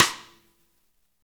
Index of /90_sSampleCDs/Roland L-CDX-01/KIT_Drum Kits 1/KIT_R&R Kit 2
SNR WOODY 08.wav